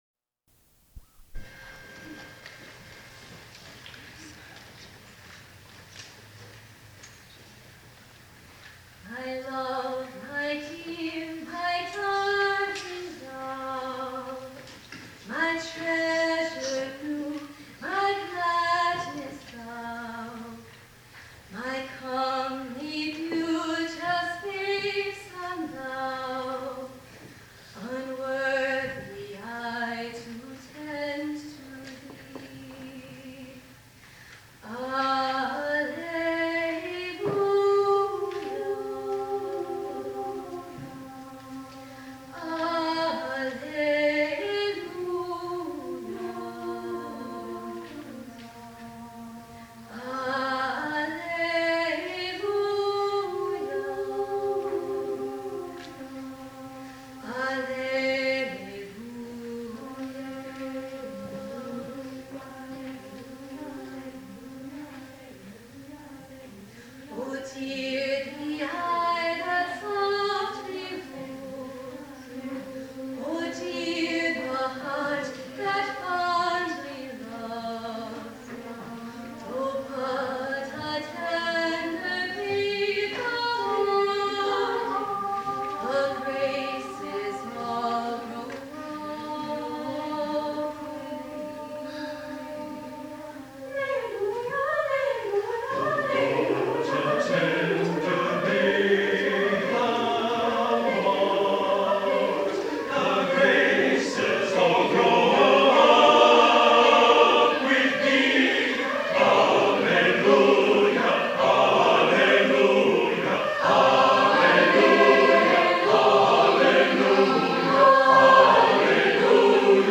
for SATB Chorus and Solo Alto (1988)
She sings alone at the end.